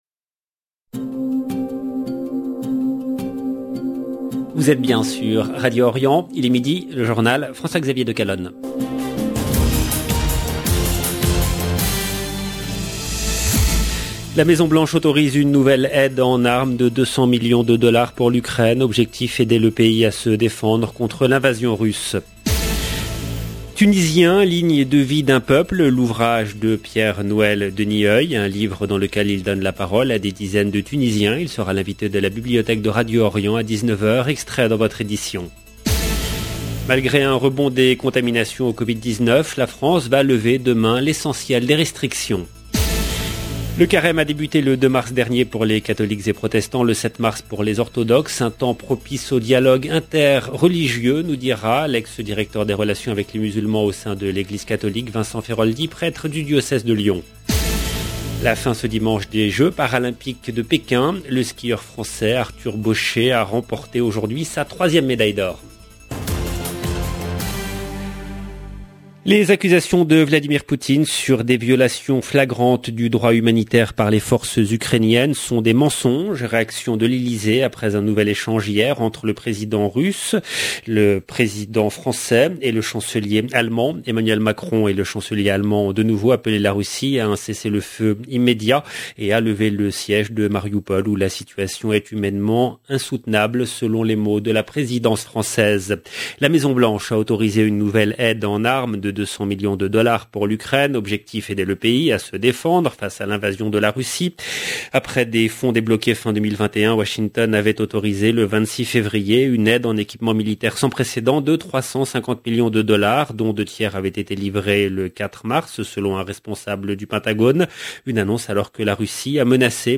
EDITION DU JOURNAL DE 12 H EN LANGUE FRANCAISE DU 13/3/2022